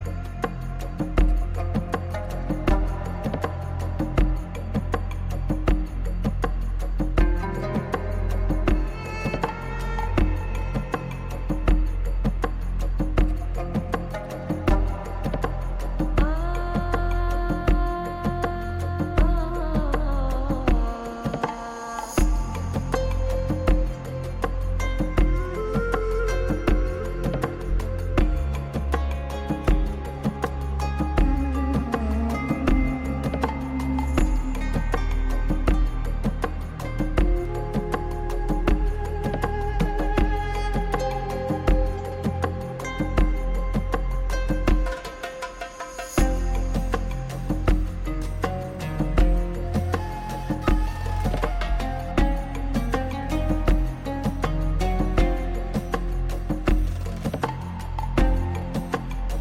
埃及鼓號.mp3